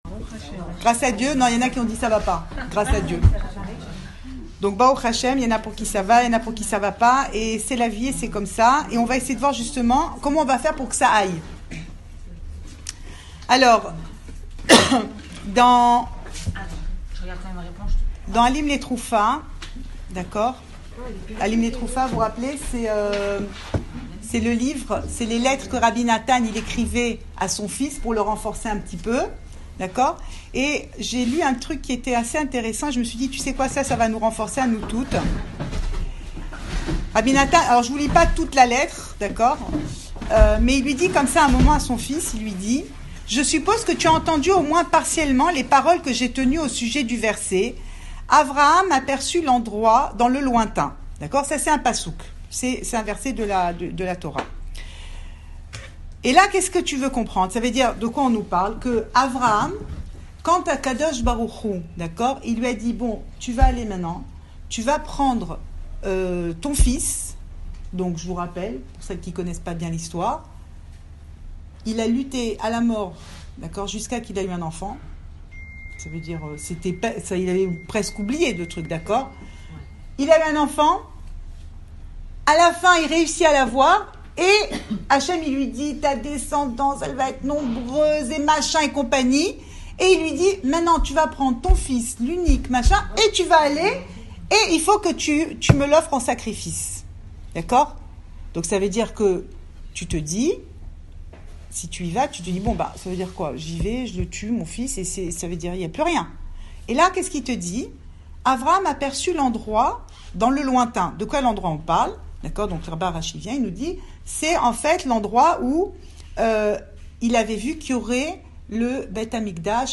L’illogisme total Cours audio Le coin des femmes Pensée Breslev
Enregistré à Raanana